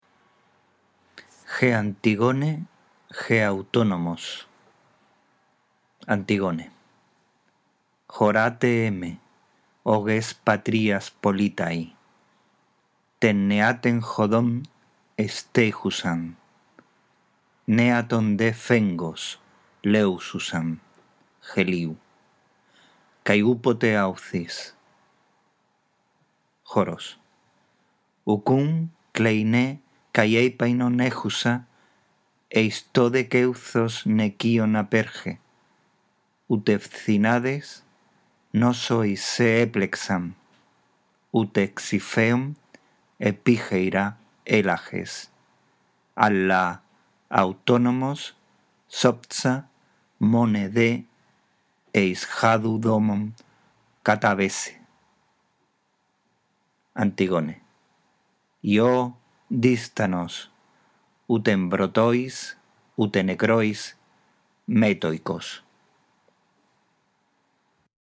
La audición de este archivo te guiará en la lectura del texto griego